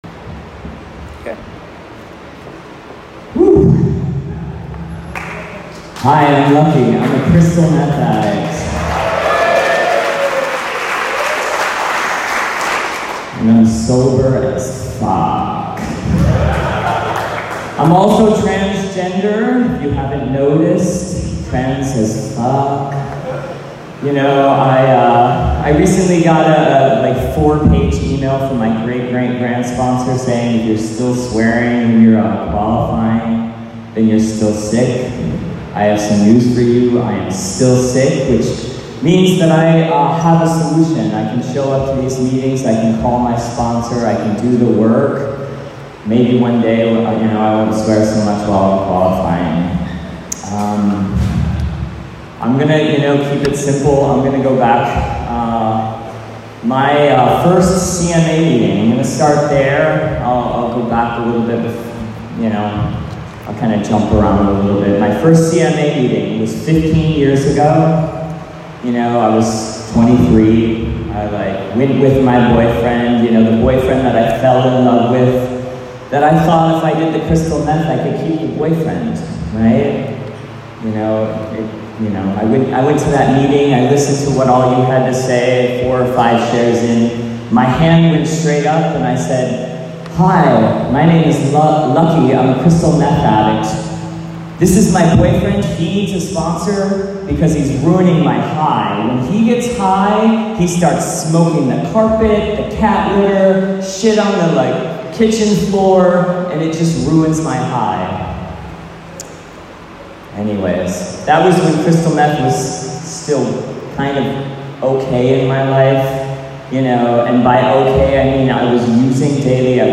CMA Speaker